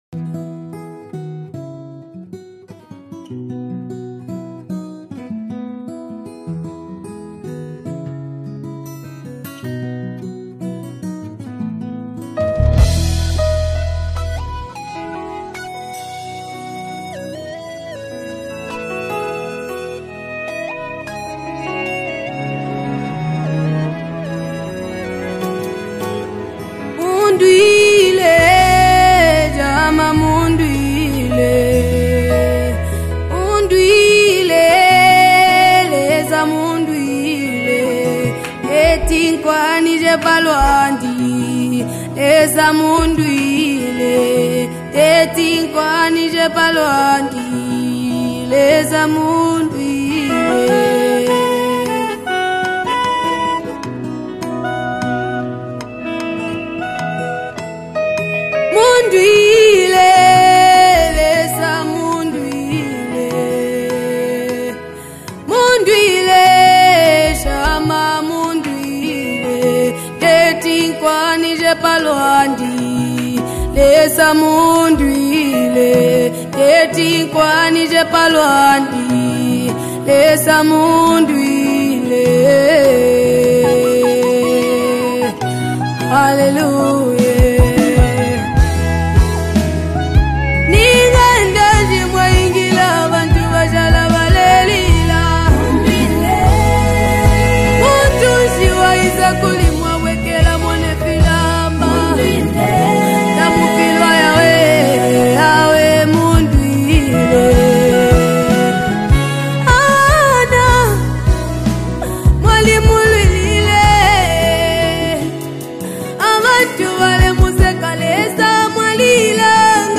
With its deep lyrics and moving melody